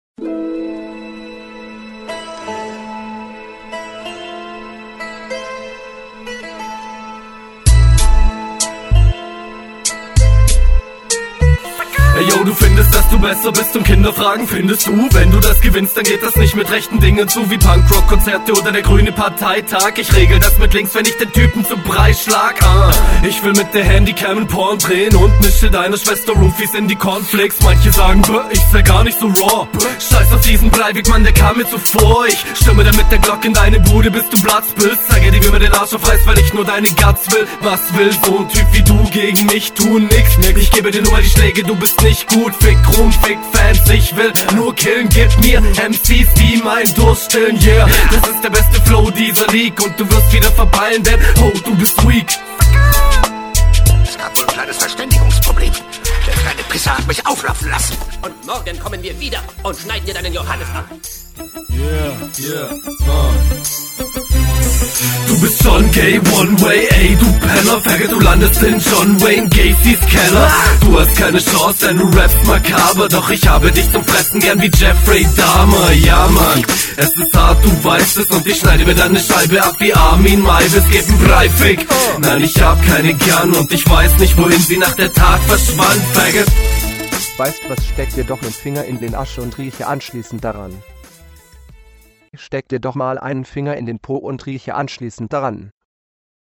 die flowpassagen gefallen mir sehr gut, echt nice, soundmix gefällt mir hier besser als in …
Yo erster beat echt nice! Flowlich bist du hier auch heftig unterwegs gerade die ersten …